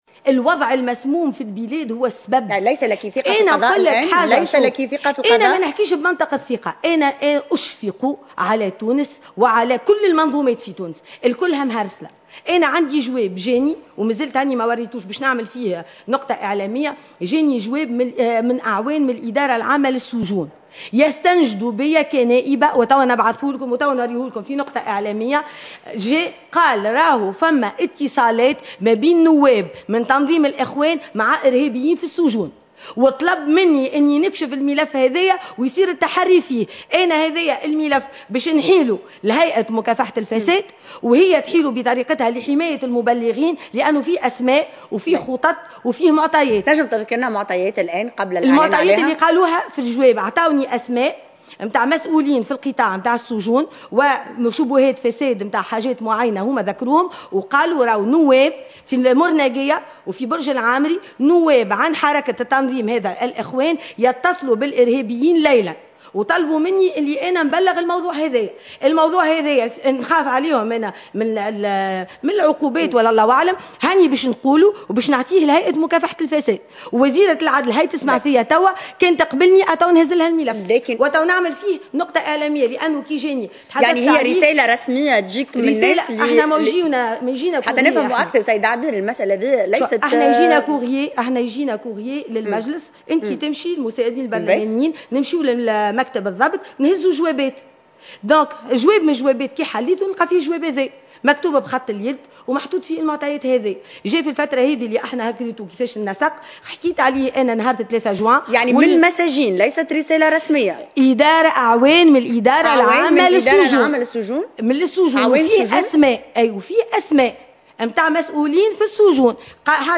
عبير موسي تعقد نقطة صحفية